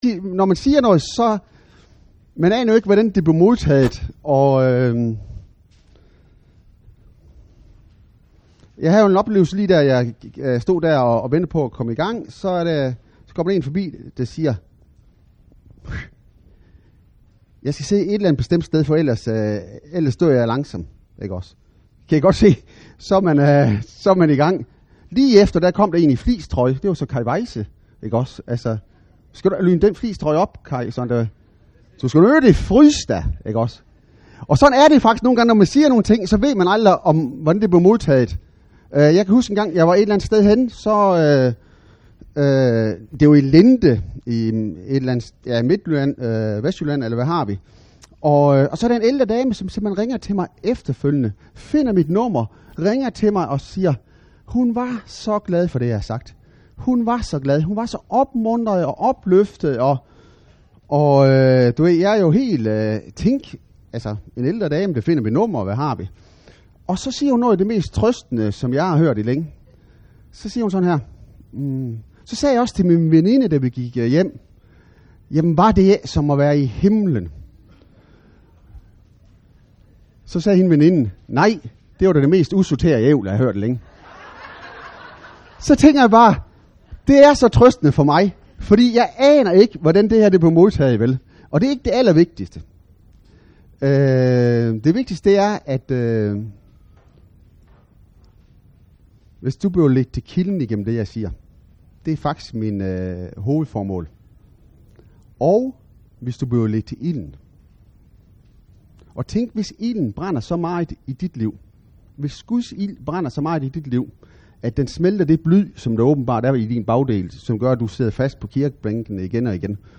Taler og undervisning fra bibeltimer, aftenmøder og udvalgte seminarer på Indre Missions bibelcamping i Hjallerup.